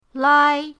lai1.mp3